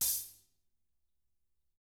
Index of /90_sSampleCDs/ILIO - Double Platinum Drums 2/Partition D/THIN A HATD